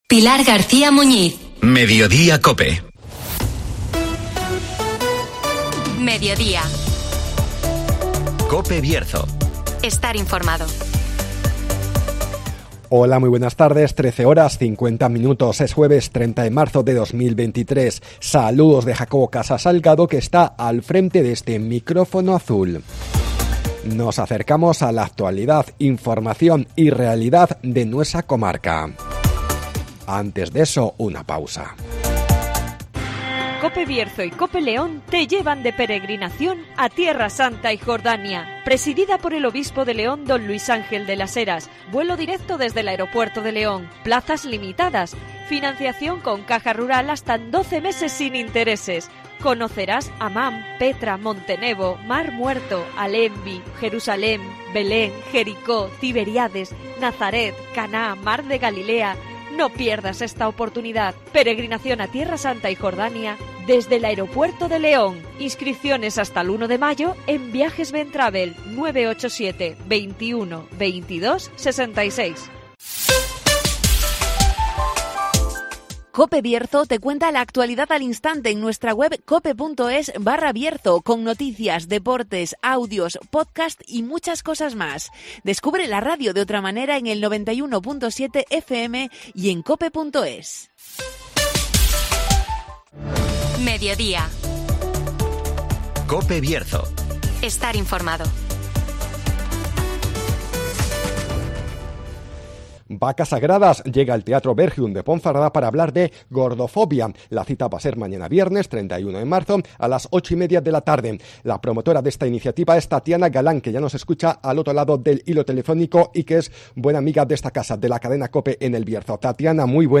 'Vacas Sagradas' llega al Teatro Bergidum de Ponferrada para hablar de gordofobia (Entrevista